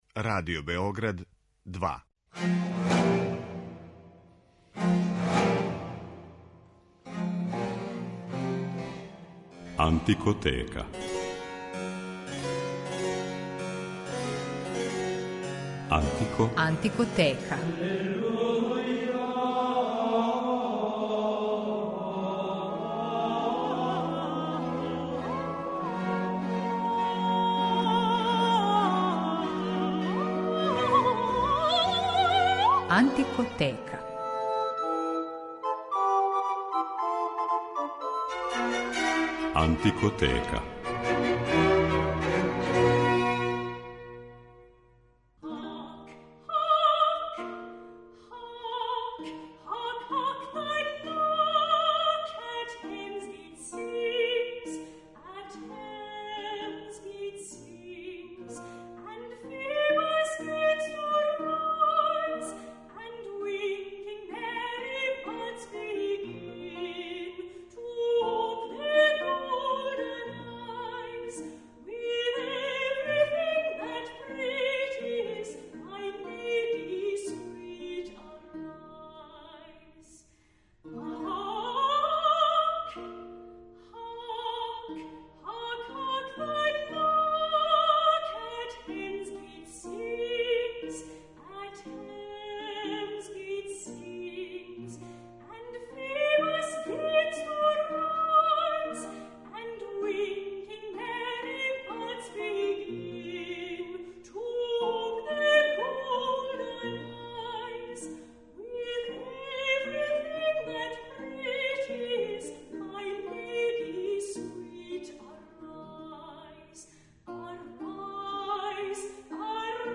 У њој ћете моћи да слушате многе од песама и игара које је овај велики писац помињао и употребљавао у својим драмама.